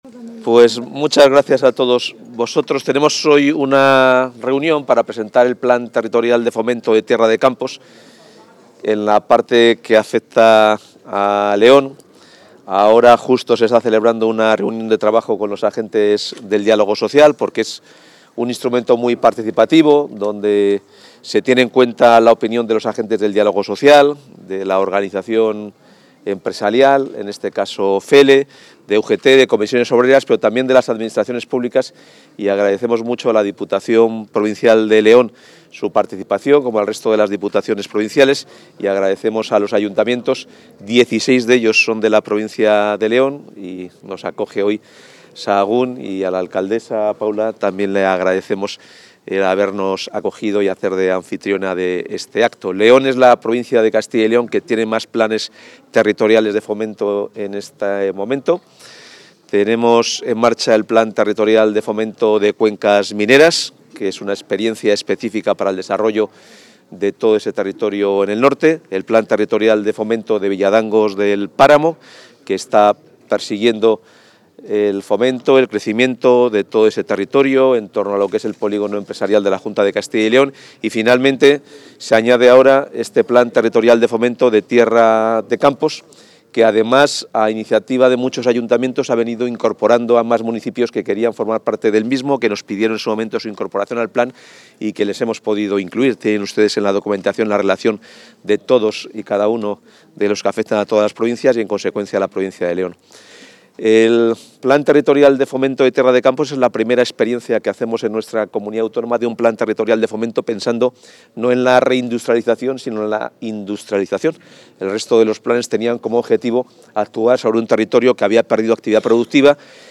Intervención del consejero.
El consejero de Economía y Hacienda, Carlos Fernández Carriedo, ha presentado en Sahagún (León) el Programa Territorial de Fomento para Tierra de Campos (2024-2031), cuyo principal objetivo es favorecer el desarrollo económico de los 206 municipios de León, Palencia, Valladolid y Zamora incluidos en el ámbito territorial de este programa de impulso a la actividad empresarial y el empleo.